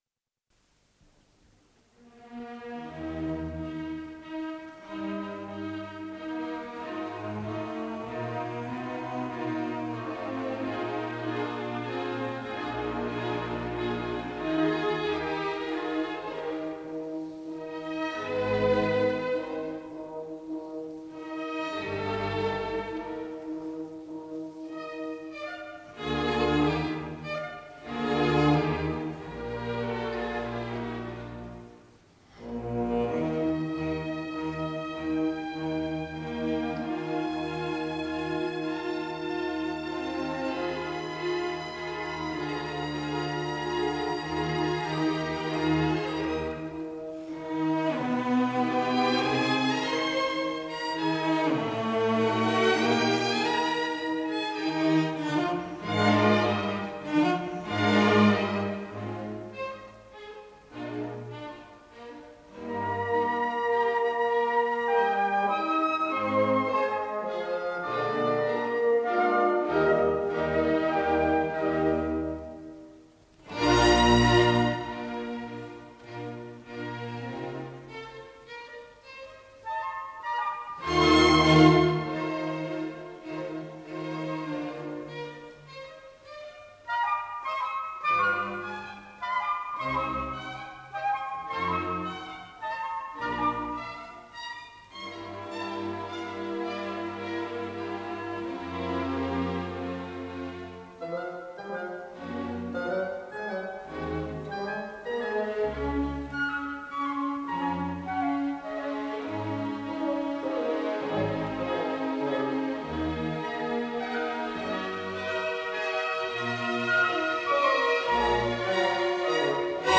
с профессиональной магнитной ленты
ПодзаголовокСоль минор
Содержание2. Анданте
ИсполнителиБольшой симфонический оркестр Всесоюзного радио и Центрального телевидения
Дирижёр - Евгений Светланов
ВариантДубль моно